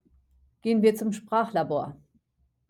(SPRACH-la-bor